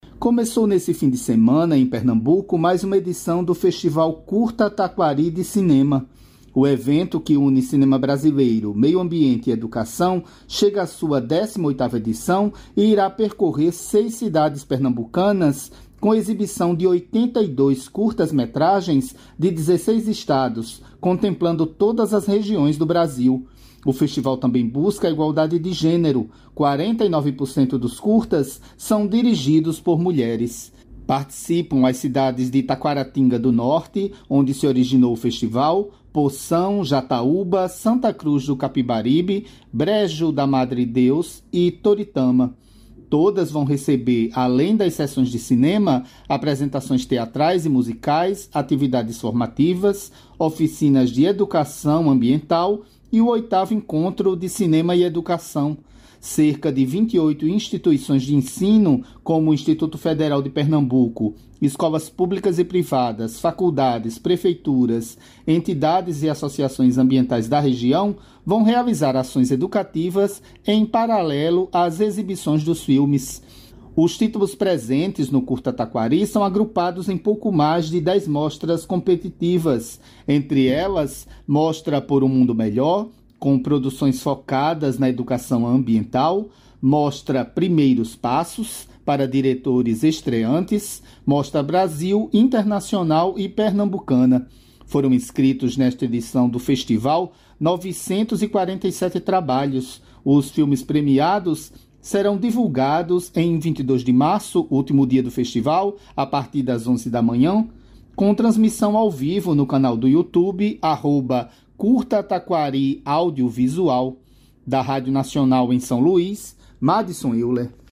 Médica alerta para risco do câncer de ovário e fala sobre cuidados